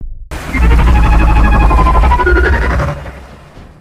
Tiamatroar.mp3